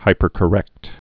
(hīpər-kə-rĕkt)